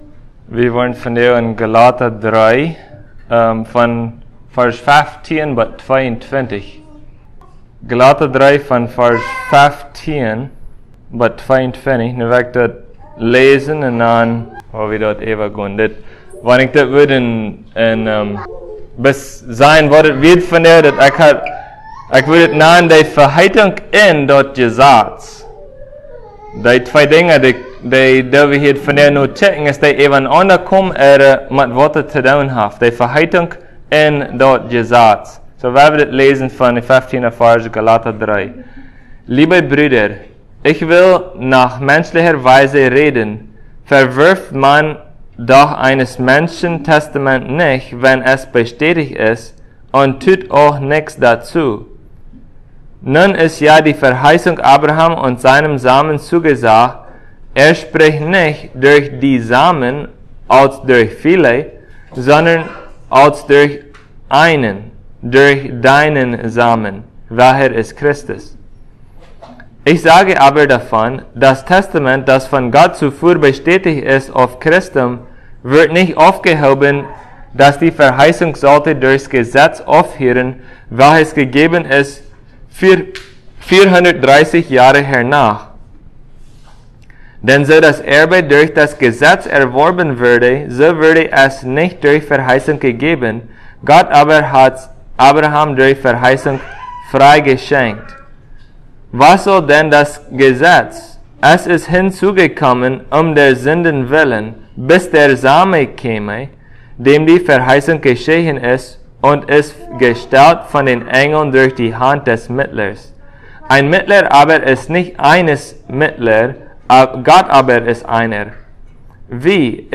Passage: Gal 3:15-22 Service Type: Sunday Plautdietsch « Christ